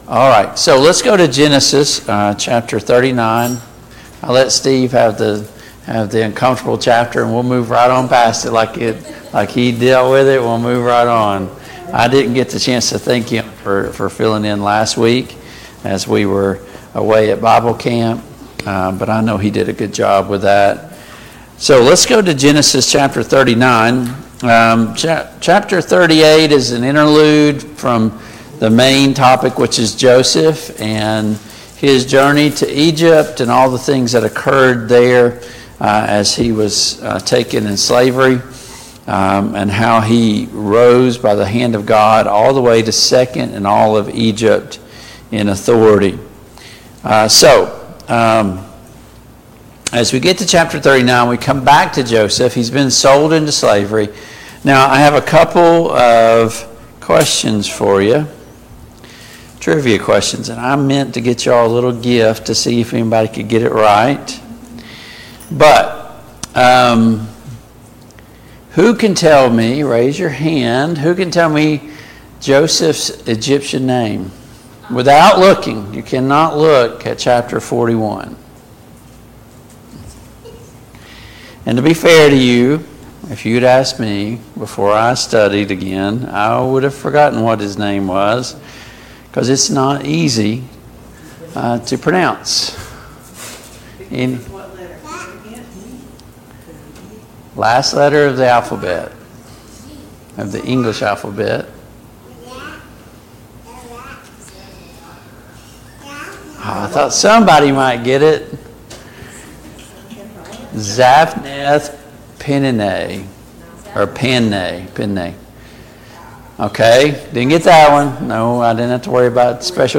Study of Genesis Passage: Genesis 39 Service Type: Family Bible Hour « What drives you?